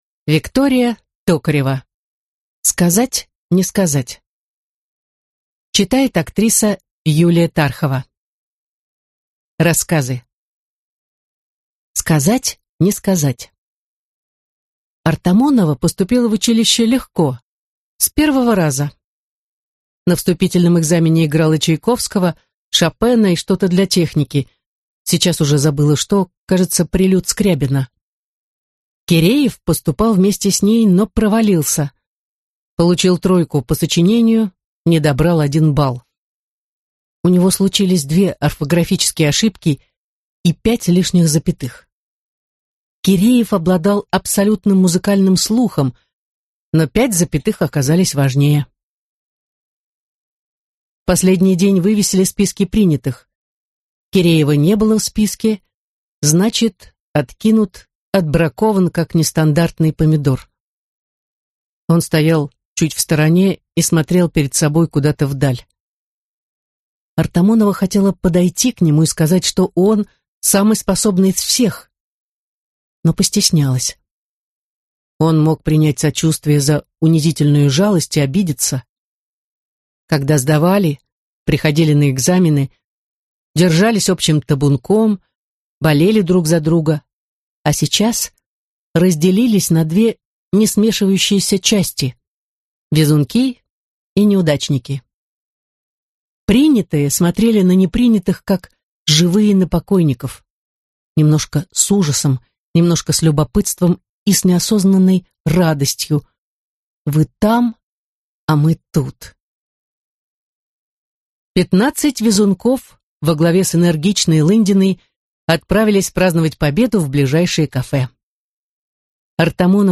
Аудиокнига Сказать – не сказать… (сборник) | Библиотека аудиокниг